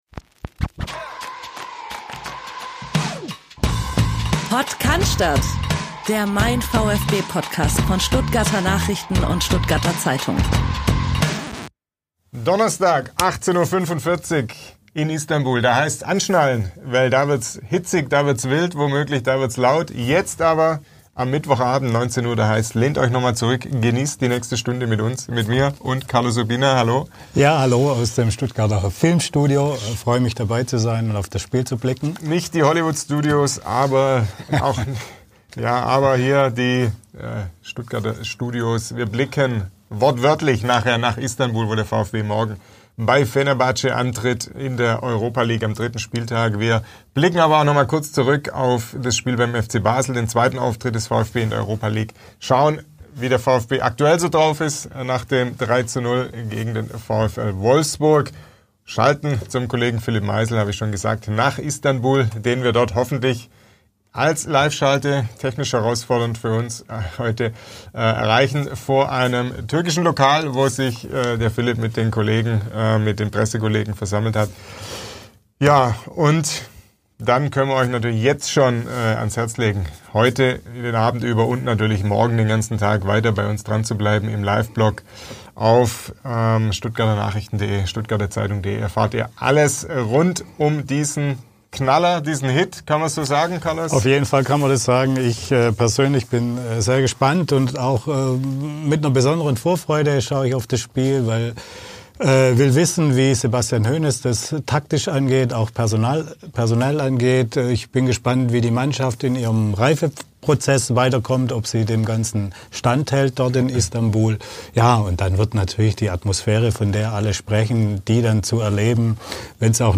Beschreibung vor 5 Monaten Es handelt sich um ein Audio-Re-Live des YouTube-Streams von MeinVfB.